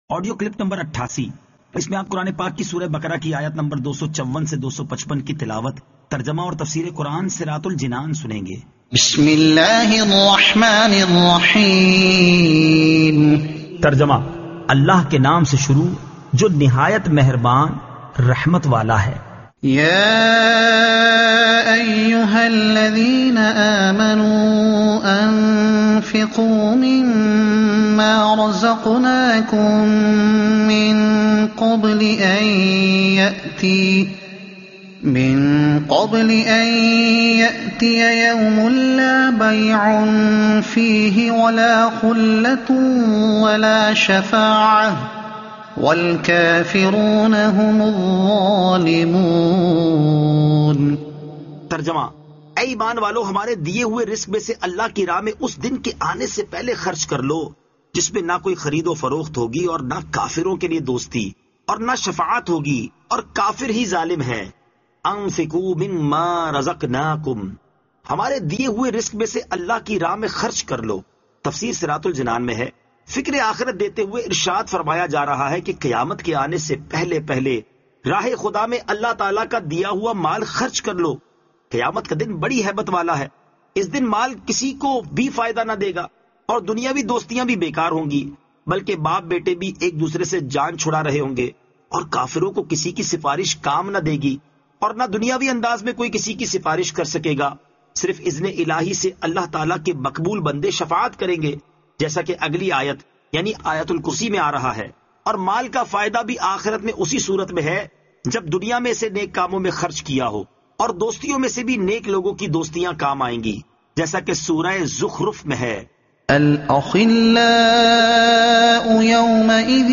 Surah Al-Baqara Ayat 254 To 255 Tilawat , Tarjuma , Tafseer